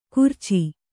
♪ kurci